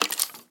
Minecraft Version Minecraft Version 1.21.4 Latest Release | Latest Snapshot 1.21.4 / assets / minecraft / sounds / mob / skeleton / step4.ogg Compare With Compare With Latest Release | Latest Snapshot